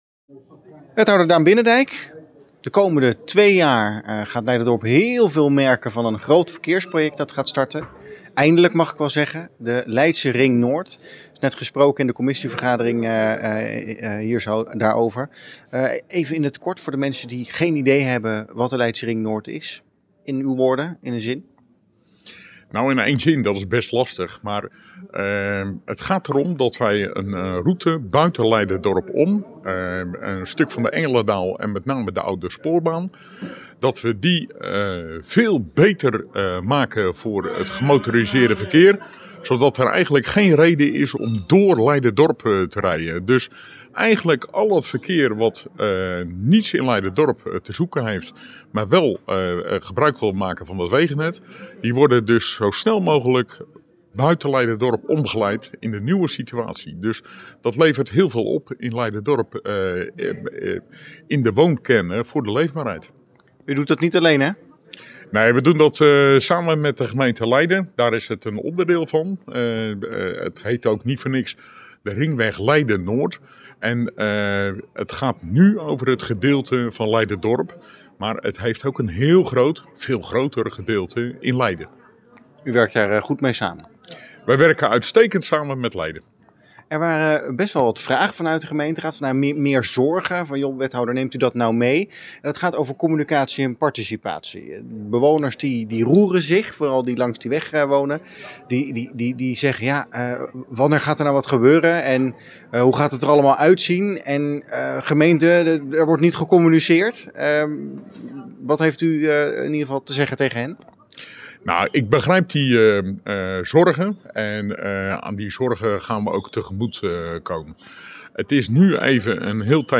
Verslaggever
in gesprek met wethouder Daan Binnendijk over de Leidse Ring Noord.
Daan-Binnendijk-over-Leidsche-ring-Noord.mp3